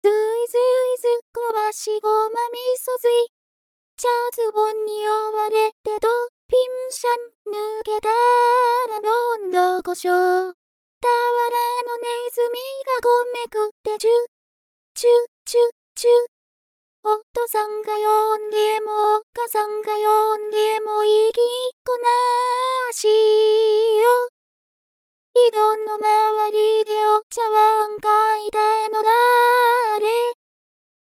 これ、昨日の夜の時点の機械っぽいバージョン
「ずいずいずっころばし」ベタ打ちver.（メグッポイド Whisper）